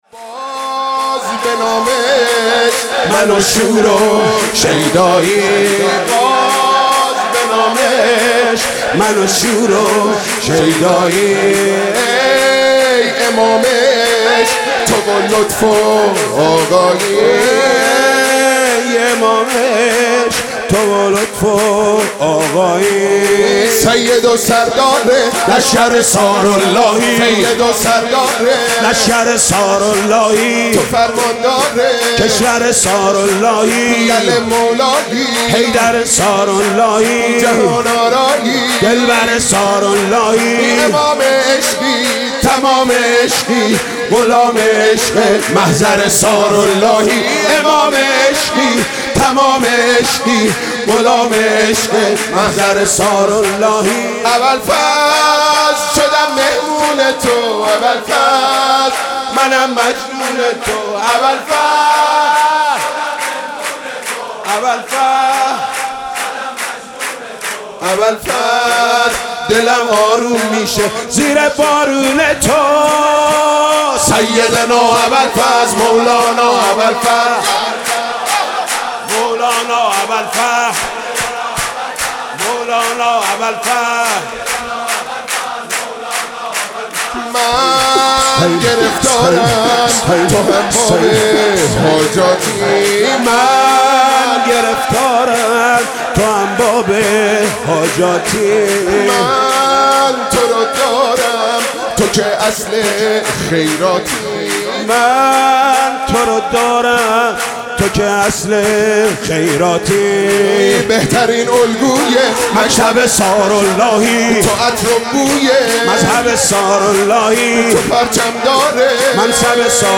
سرود: باز به نام عشق من و شور و شیدایی